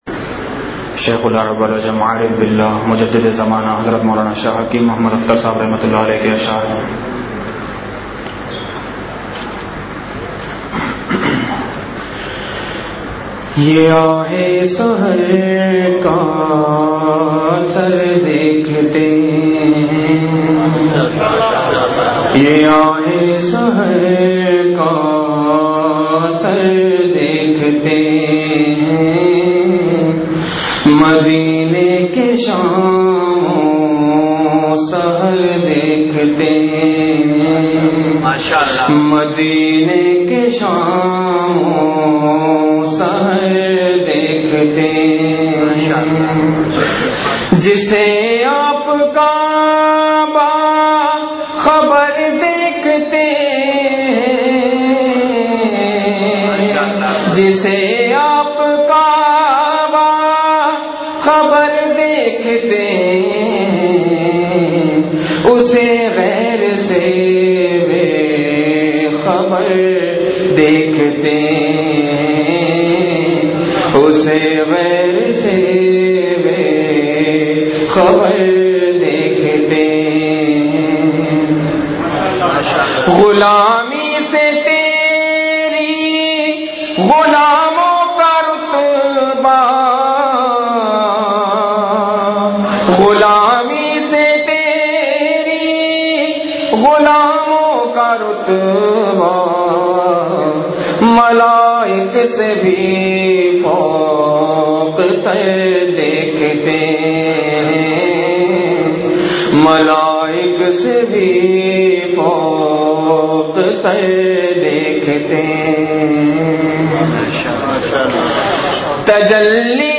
اشعار کے بعد حضرت شیخ دامت برکاتہم نے درد بھرا بیان فرمایا۔